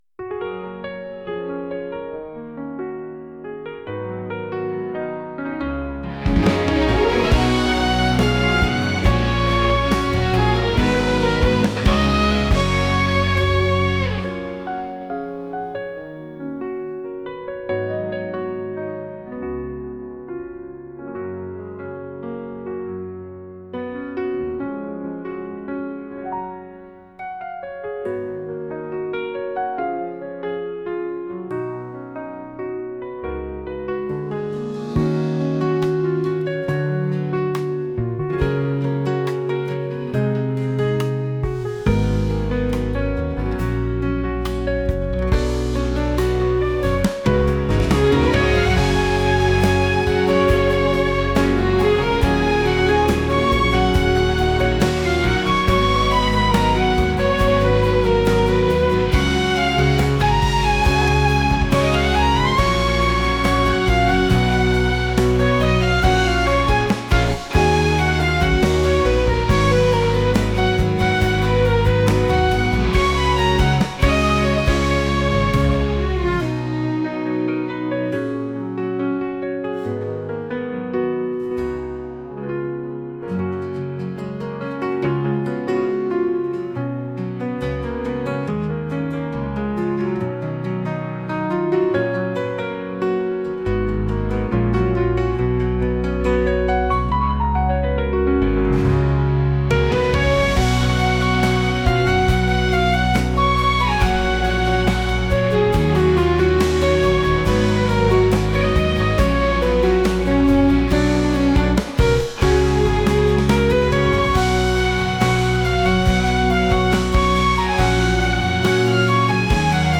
春が萌えいずるようなピアノの曲です。